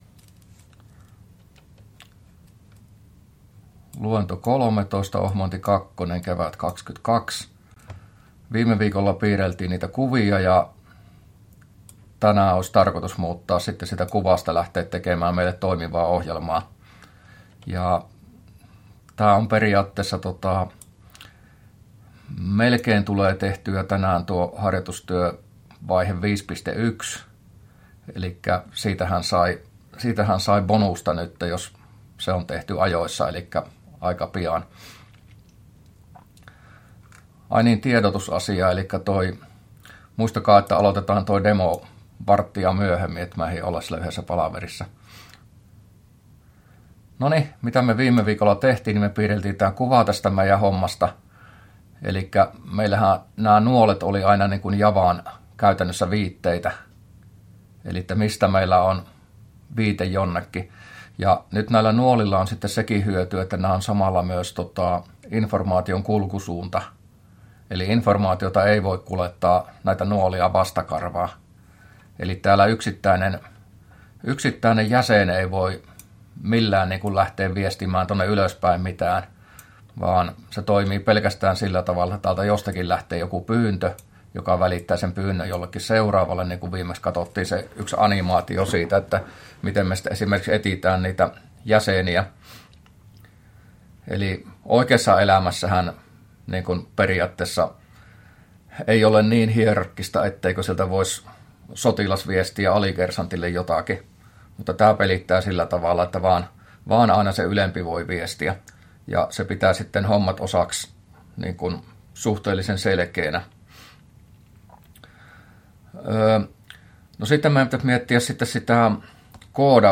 luento13a